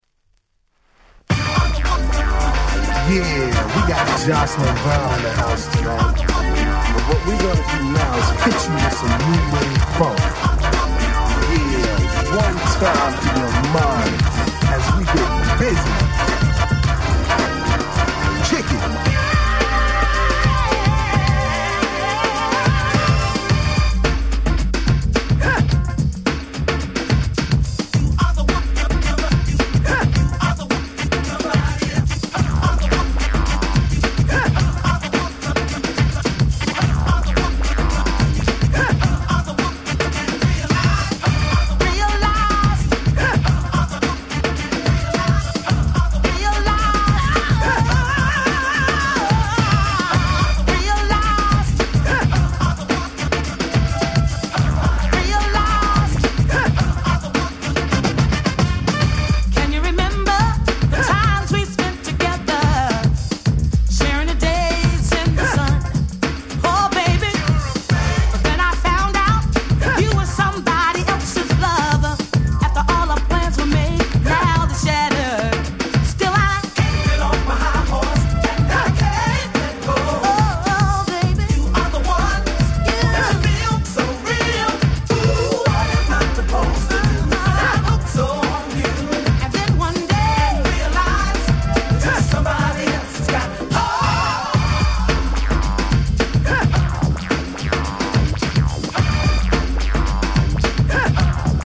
Super Dance Classics !!